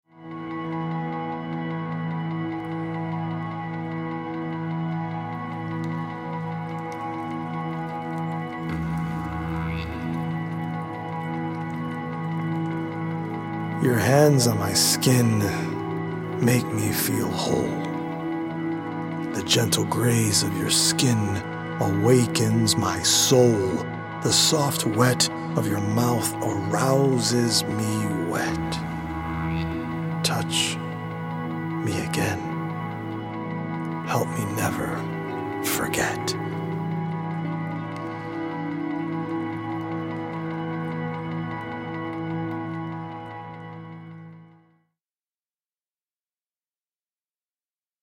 The First Audio-Visual Generative Poetic collection on the blockchain.
healing Solfeggio frequency music